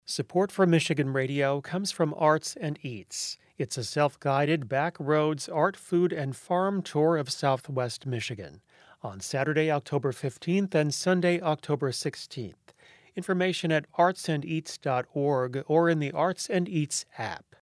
MI-Radio-Spot.mp3